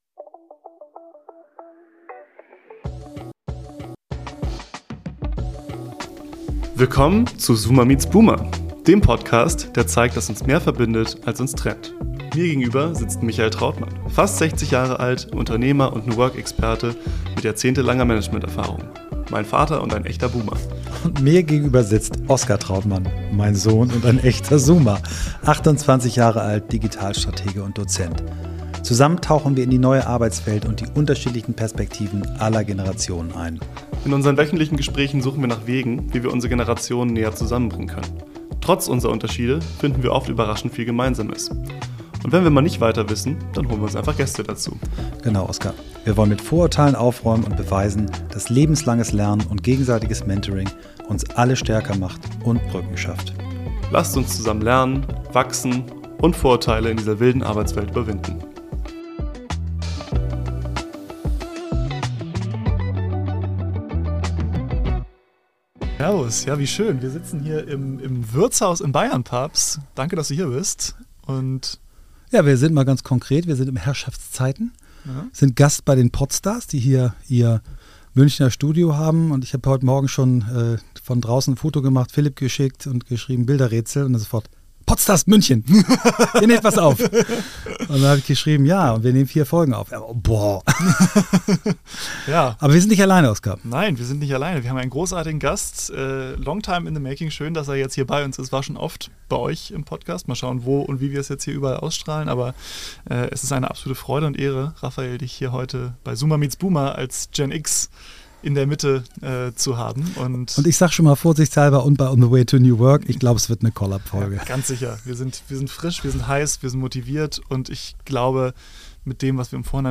Es sitzt dieses Mal die Gen X mit am Tisch.